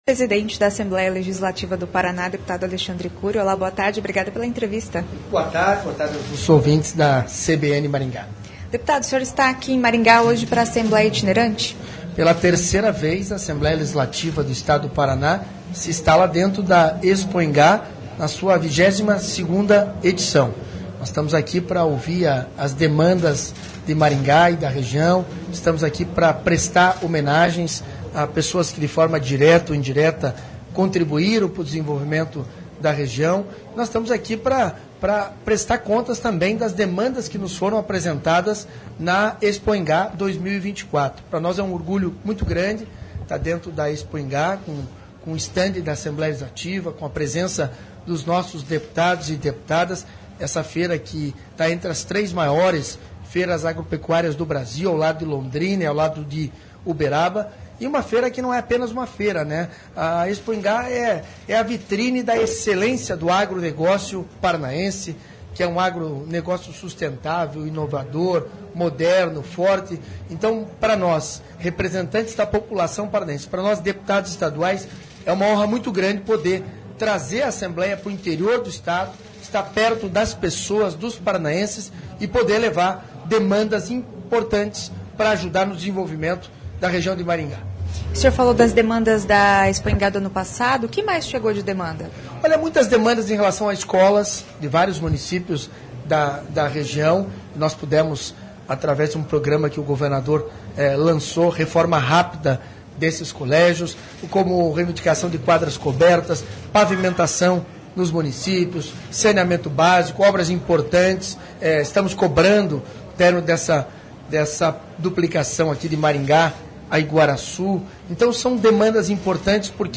Alexandre Curi, presidente da Alep.